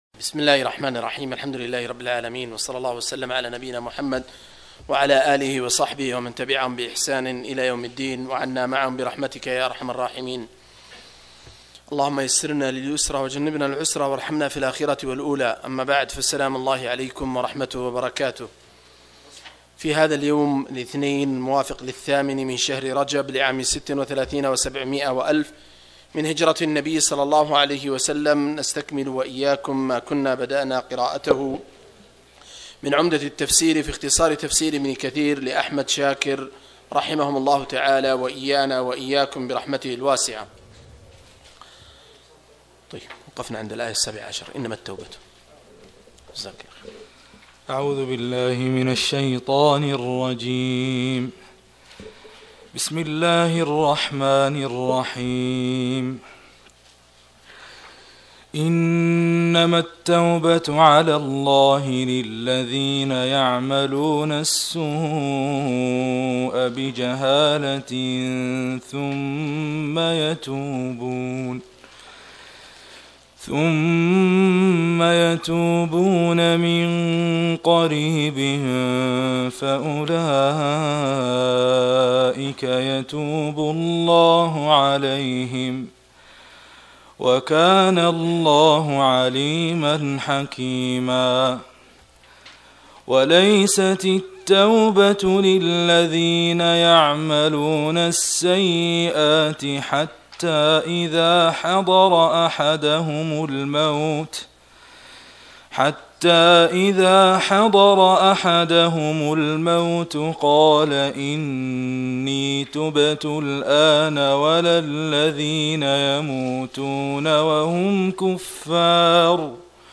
084- عمدة التفسير عن الحافظ ابن كثير رحمه الله للعلامة أحمد شاكر رحمه الله – قراءة وتعليق –